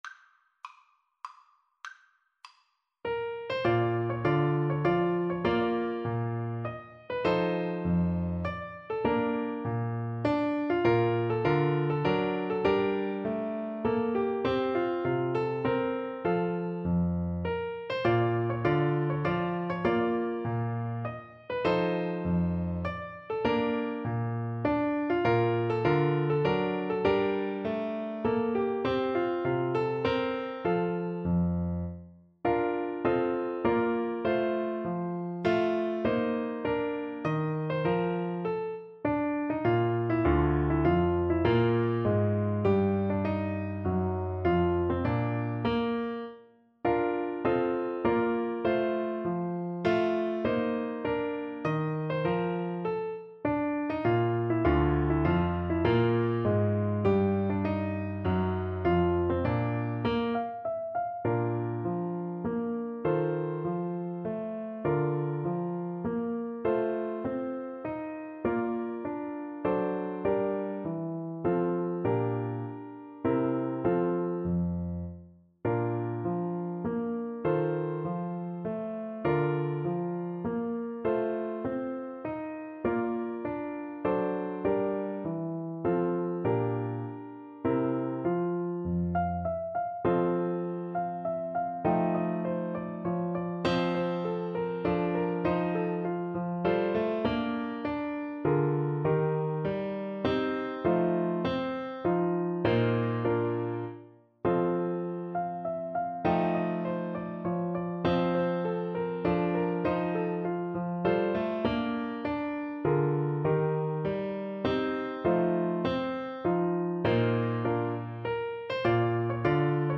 3/4 (View more 3/4 Music)
Classical (View more Classical Saxophone Music)